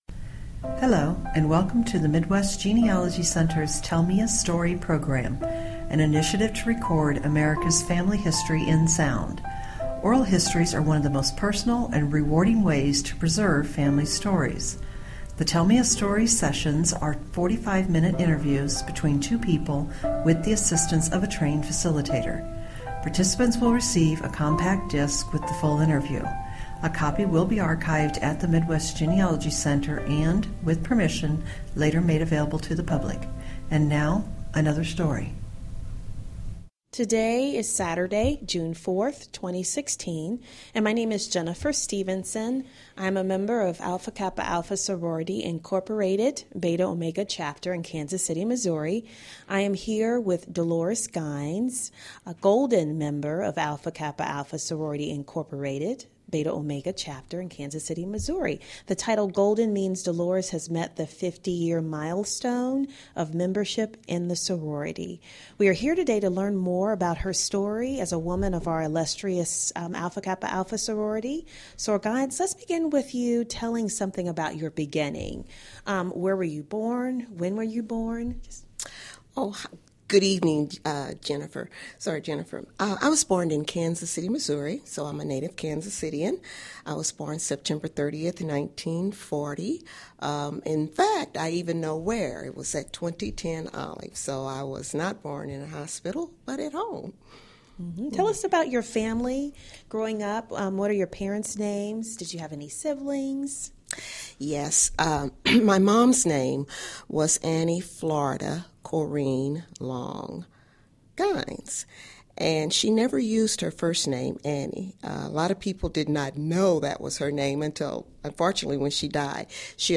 Oral history
Stereo